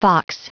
Prononciation du mot fox en anglais (fichier audio)
Prononciation du mot : fox